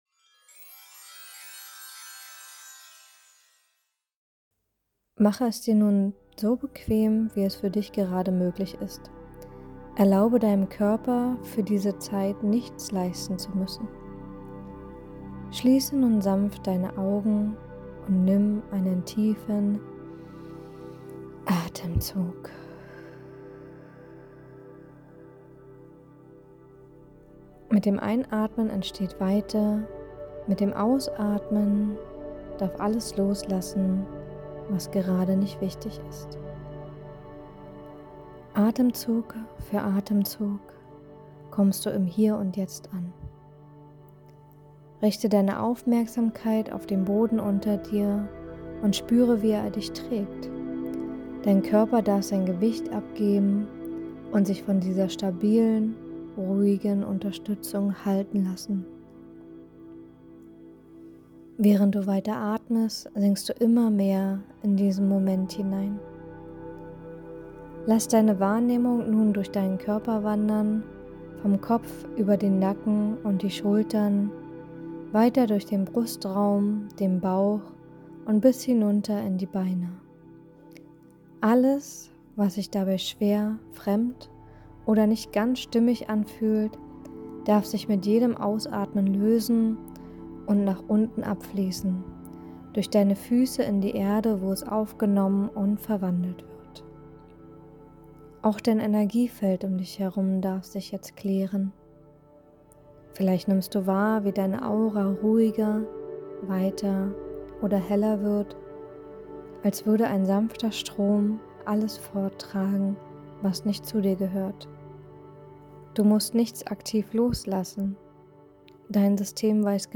Schutzmeditation
Schutzmeditation_1.mp3